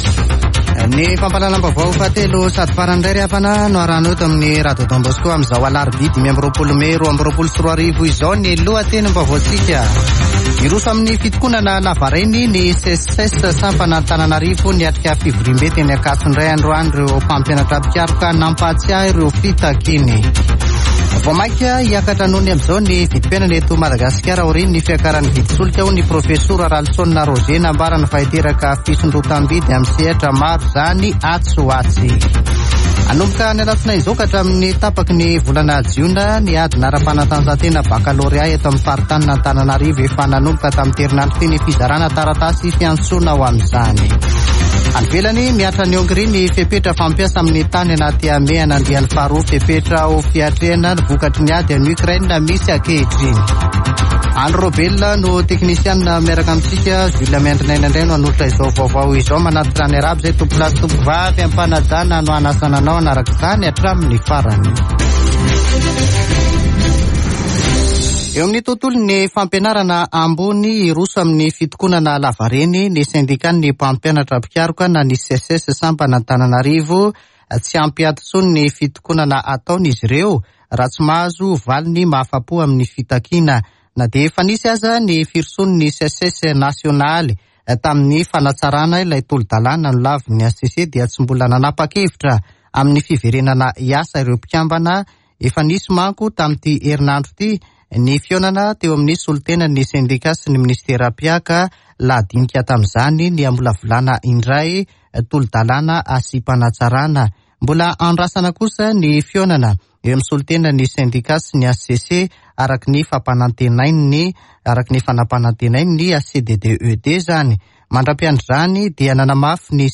[Vaovao hariva] Alarobia 25 mey 2022